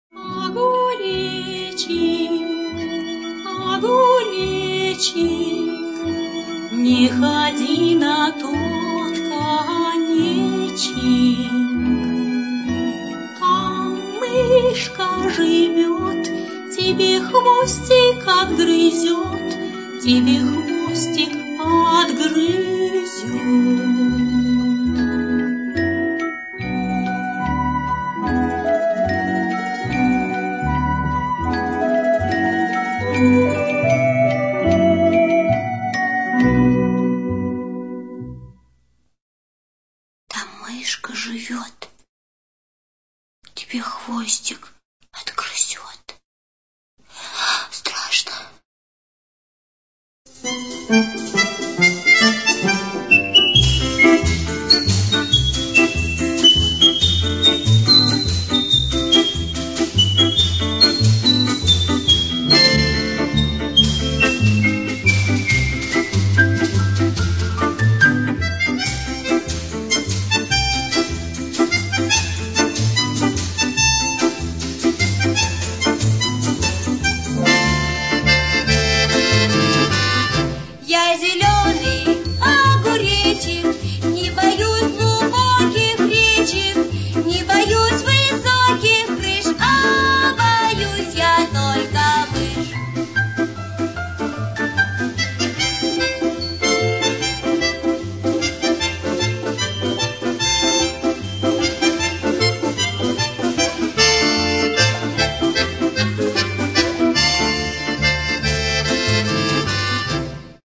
детская песня